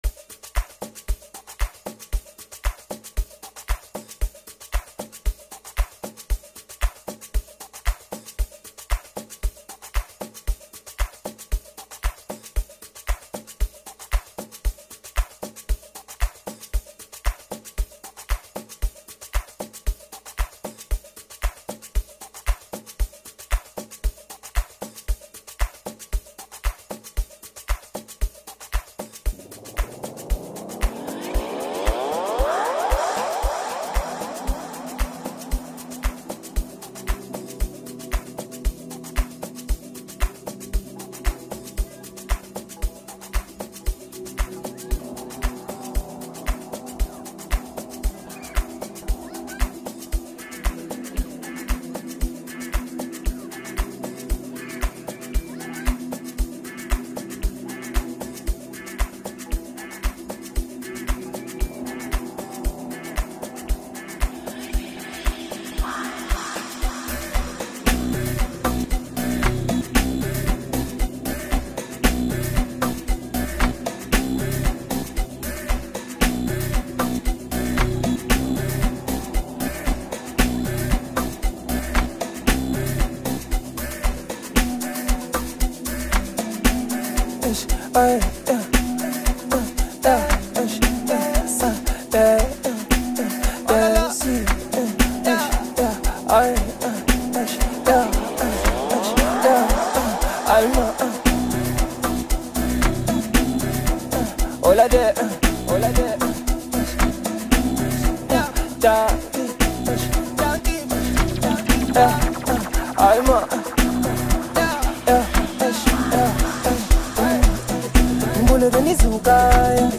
AMAPIANO Apr 07, 2026